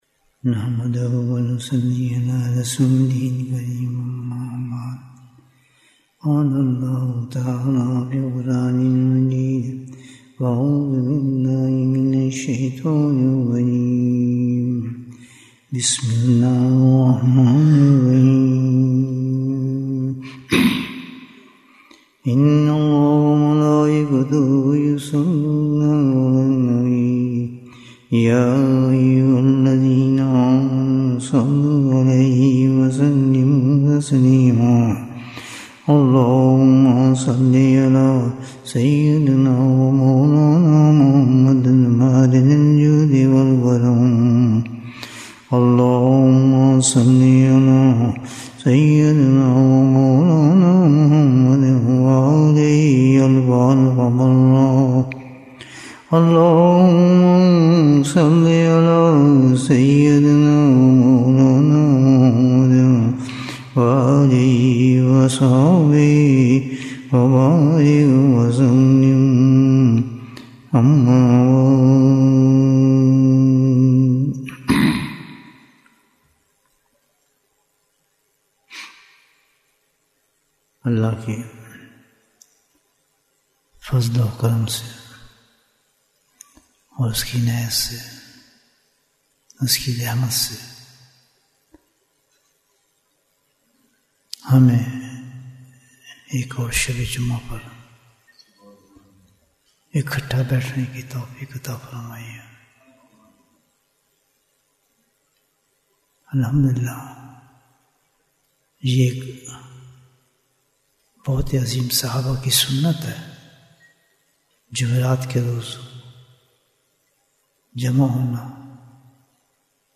Bayan, 58 minutes18th December, 2025